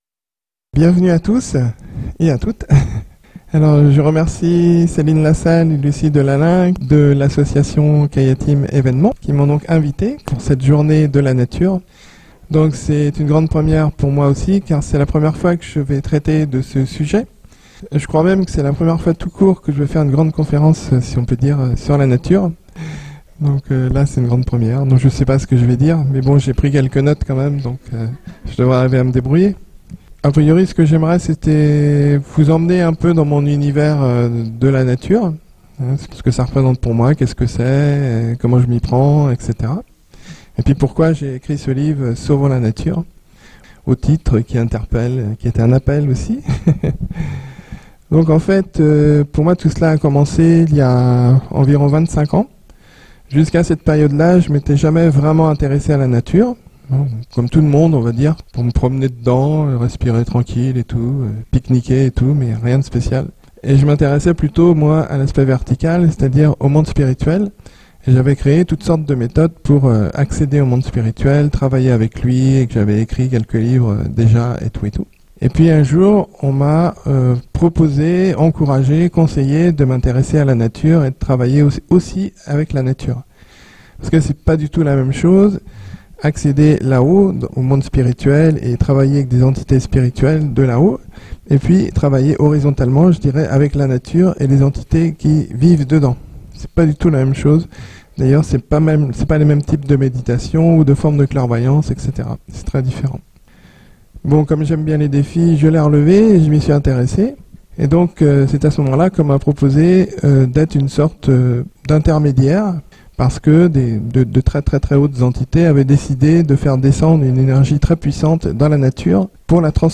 Conférence "Sauvons la Nature"